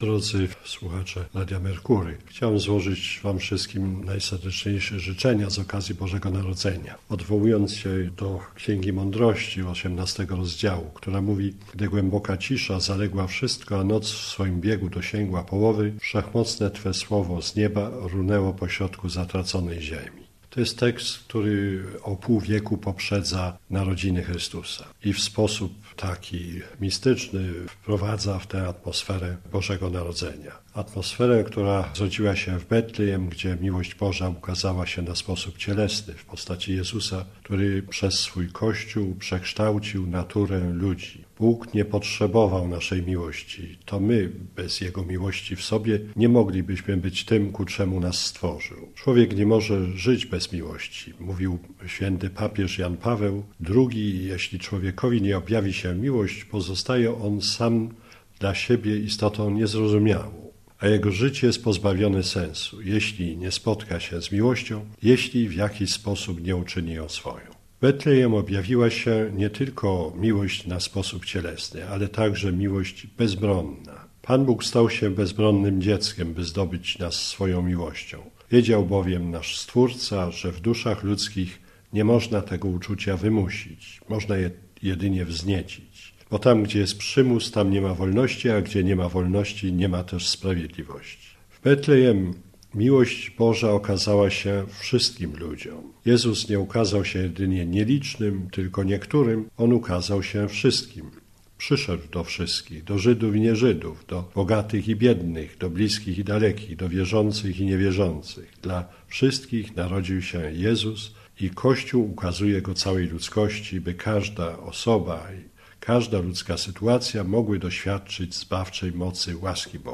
Radosnych i błogosławionych świąt - życzy Słuchaczom Radia Merkury - arcybiskup Stanisław Gądecki.